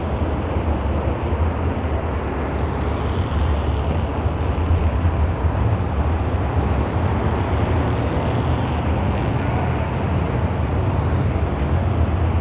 ambience_nonraceday.wav